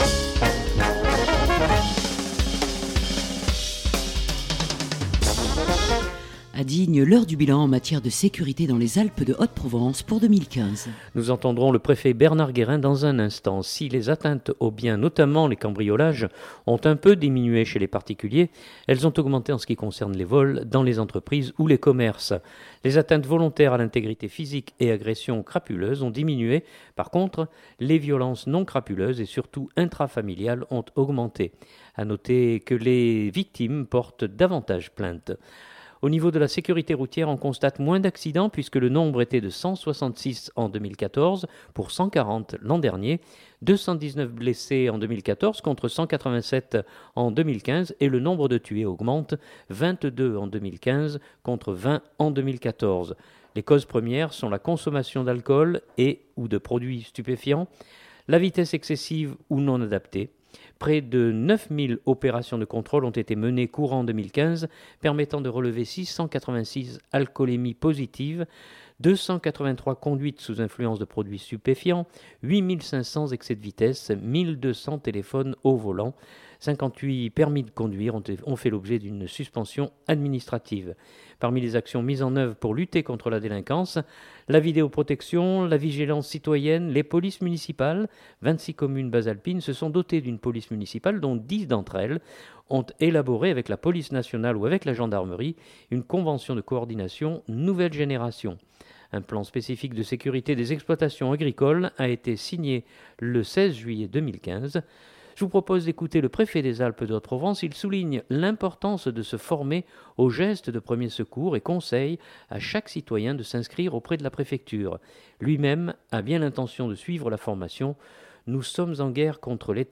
Je vous propose d’écouter le préfet des Alpes de Haute-Provence. Il souligne l’importance de se former aux gestes de premiers secours et conseille à chaque citoyen de s’inscrire auprès de la préfecture.
Reportage